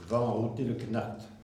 Elle provient de Saint-Gervais.
Catégorie Locution ( parler, expression, langue,... )